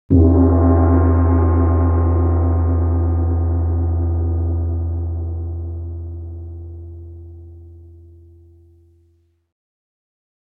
Deep Bass Gong Sound Effect Free Download
Deep Bass Gong